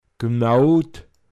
Pinzgauer Mundart Lexikon